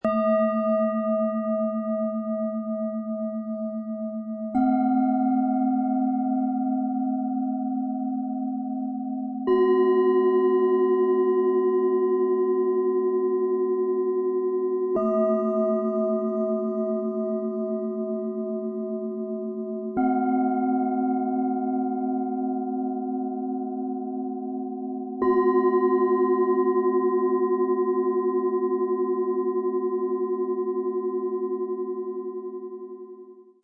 Finde den Klang deiner Verbundenheit Vom Bauch über das Herz bis zum Kopf · Klangschalen-Set Ø 12,1–14,2 cm, 0,9 kg
Mit Ø 14,2 cm und 334 g schenkt sie wärmende Vibrationen im Bauch und unteren Rücken.
Mit Ø 13,4 cm und 302 g entfaltet sie einen vollen, harmonischen Ton, der den Herzbereich anspricht.
Mit Ø 12,1 cm und 261 g erzeugt sie eine feine, prickelnde Schwingung.
Im Sound-Player - Jetzt reinhören lässt sich der Originalklang dieser drei Schalen anhören – vom warmen Bauchton über den Herzbereich bis zum klaren Kopfklang.